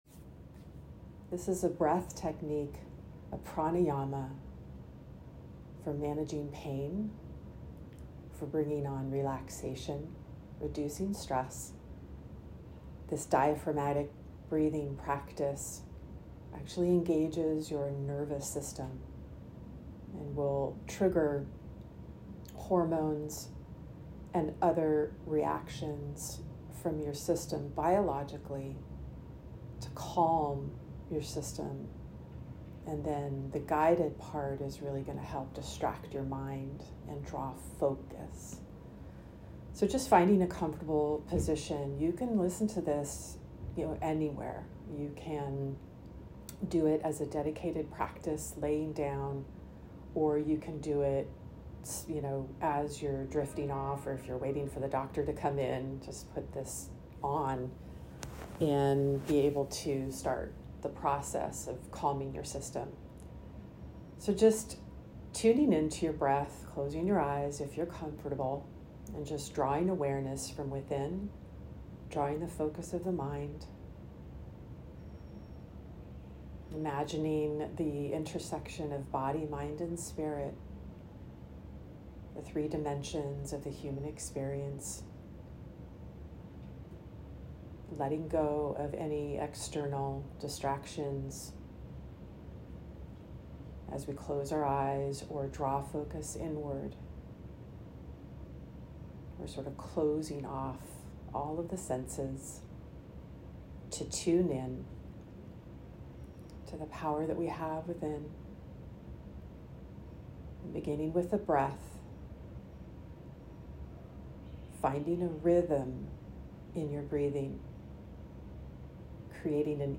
Breath-Guided-Meditation.mp3